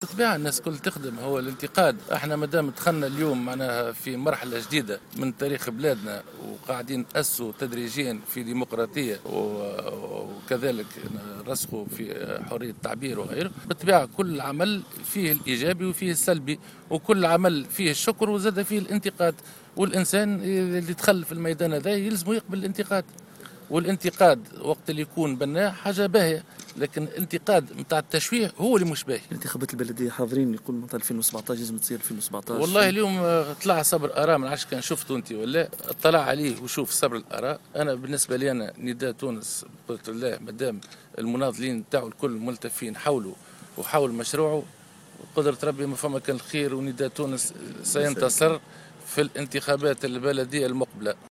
وأضاف في تصريح لمراسل الجوهرة اف ام خلال زيارة أداها اليوم إلى الوردانين بمناسبة الذكرى 65 لثورة 22 جانفي 1952، أن الانتقادات التي يتعرض لها الحزب أمر عادي بالنظر لمناخ الديمقراطية القائم في البلاد، مشددا على ضرورة أن يتقبل كل طرف سياسي النقد البنّاء ليحقق التقدم، وفق تعبيره.